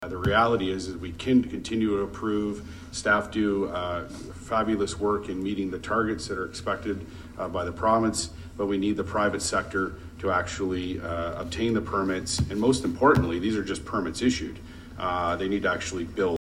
Councillor Paul Carr told council Tuesday that there have only been nine building permits for new residential units to the end of March this year and that wasn’t enough.